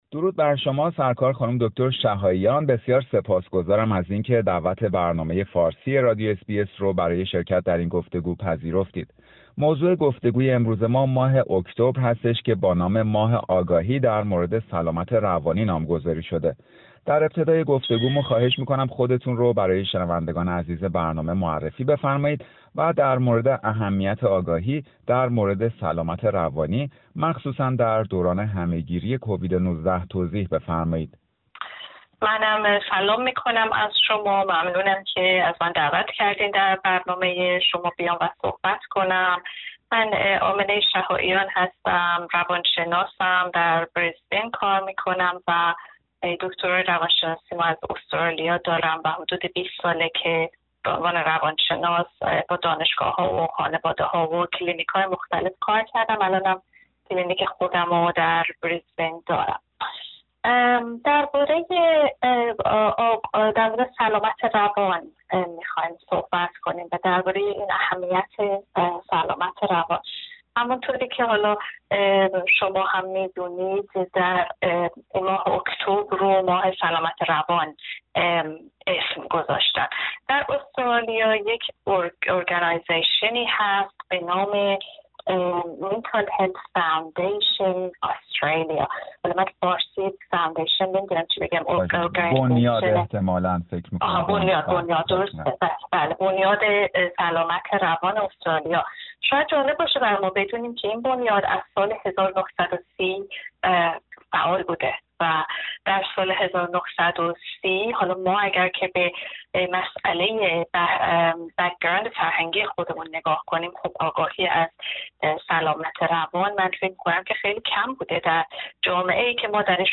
برنامه فارسی رادیو اس بی اس گفتگویی داشته